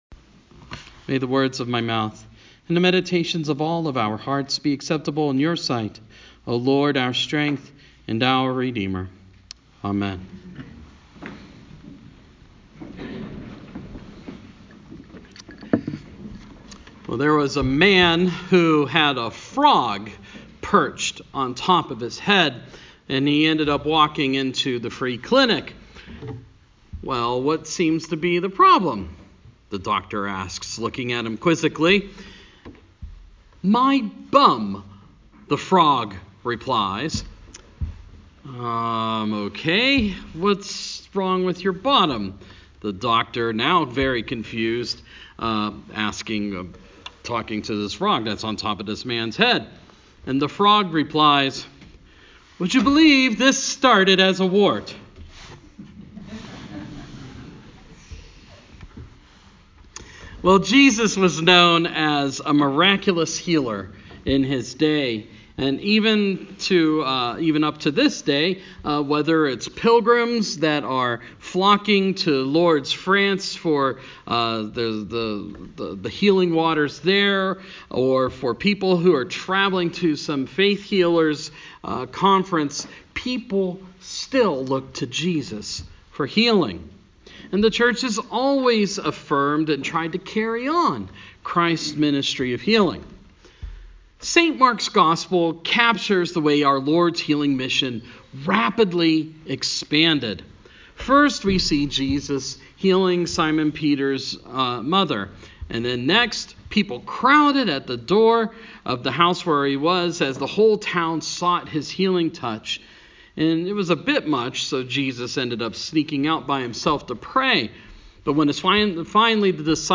5th Sunday after Epiphany